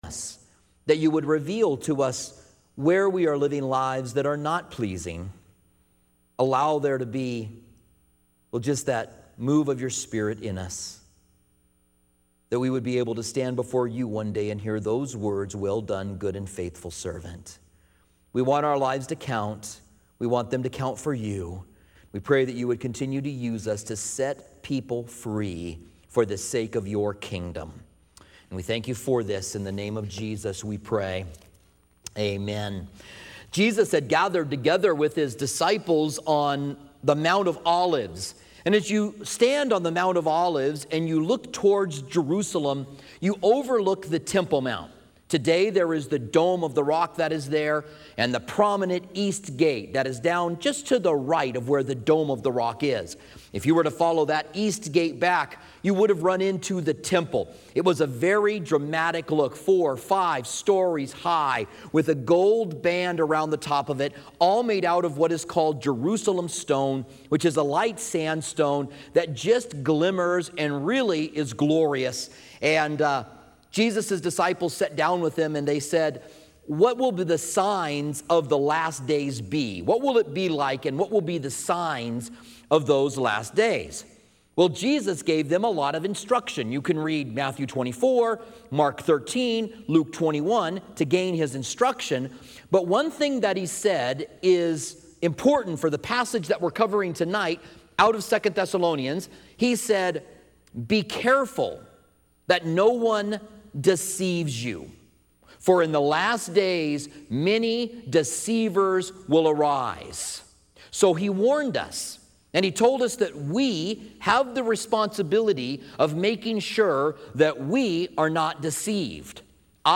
Commentary on 2 Thessalonians